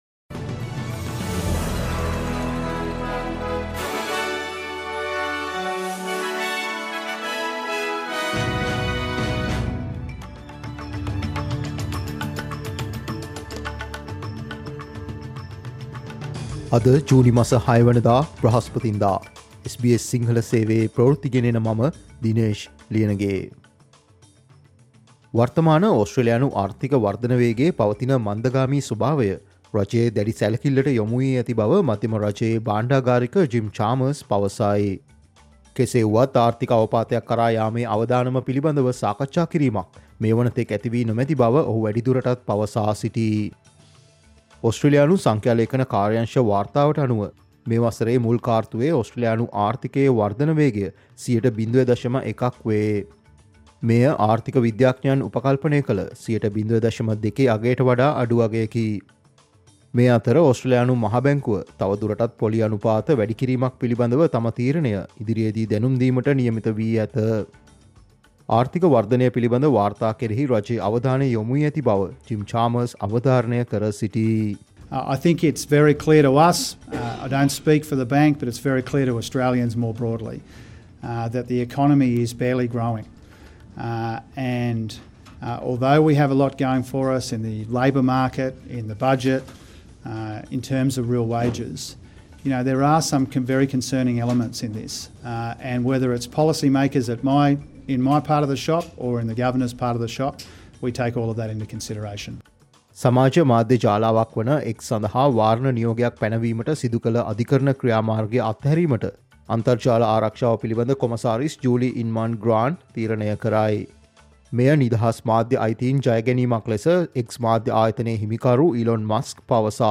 Australia's news in Sinhala, Listen, SBS Sinhala News Flash today